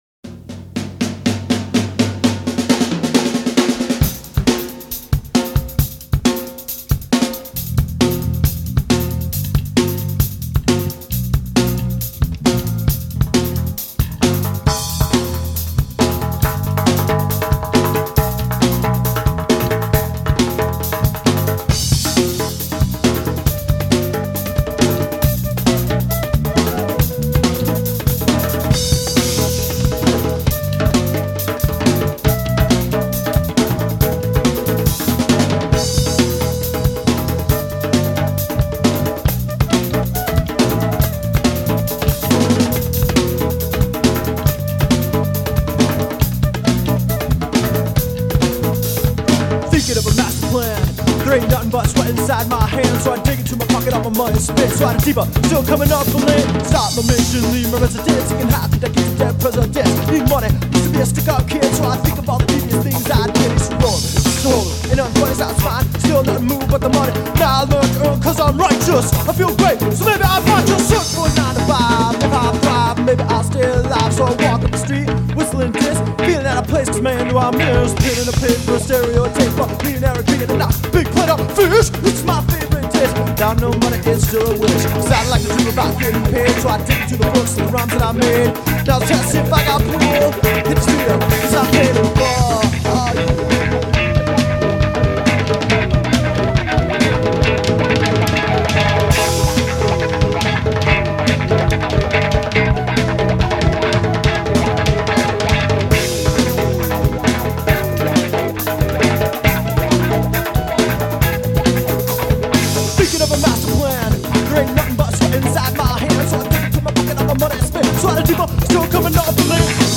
Vocal/Guitar
Bass
Percussion
Drums